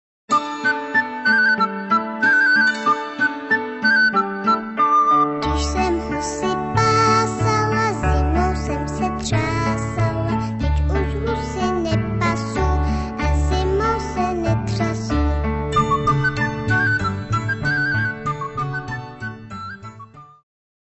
lidová
zpěv